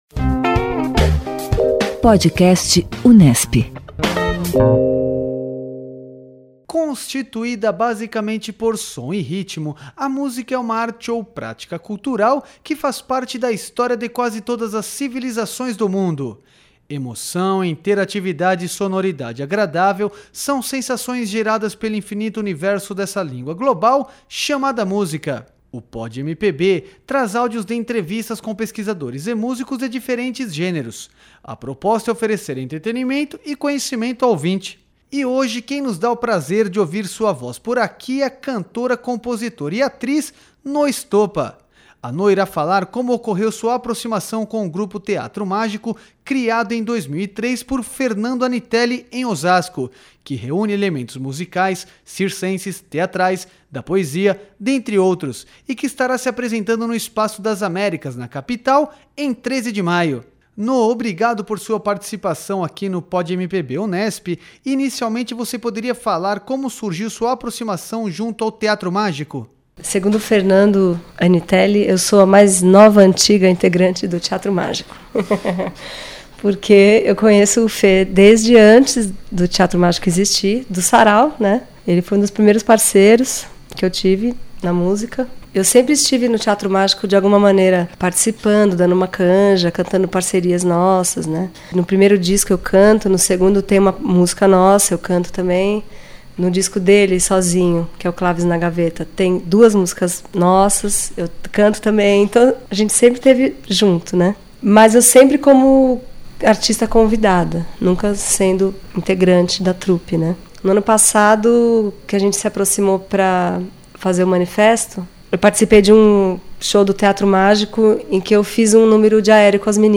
A entrevista conta com a faixa "Quente" do álbum Manifesto Poesia.